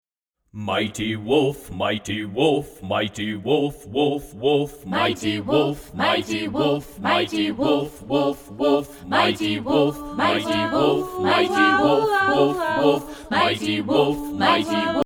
Cheerful songs jubilantly performed